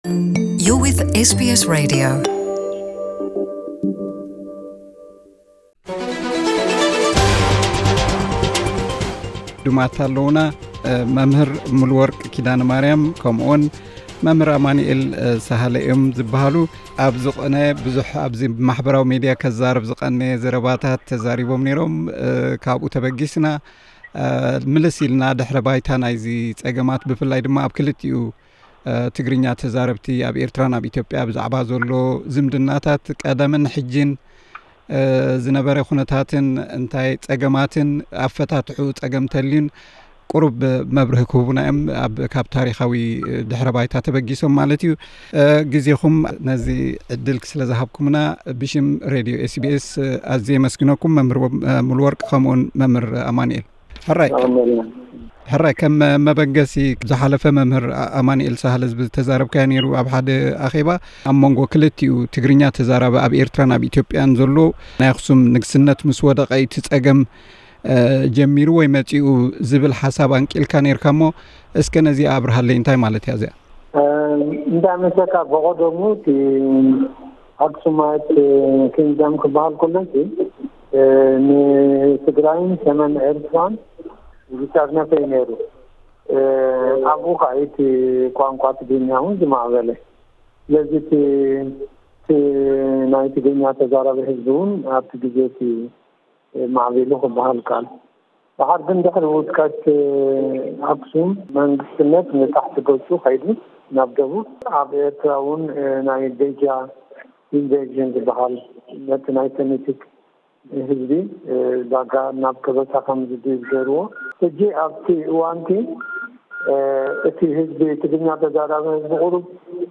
ኣብ ዘተ ኣቕሪብናዮም ኣለና። ንምንታይ ኣብዚ እዋን ብወገን ተጋሩ ሕውነት ምስ ኤርትራ ብፍላይ ድማ ምስ ተዛረብቲ ትግርኛ ብጽዑቕ ይጉስጎሰሉ ኣሎ? ብወገን ኤርትራዊያንከ እዚ ስምዒት ኣሎ ድዩ?